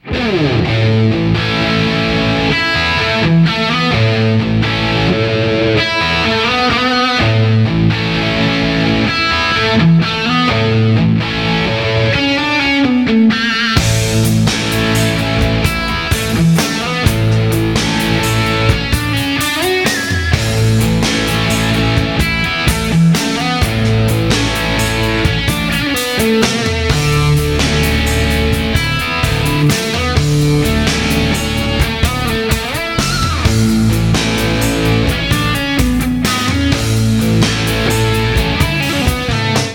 • Качество: 200, Stereo
без слов
инструментальные
электрогитара
Один из лучших гитаристов мира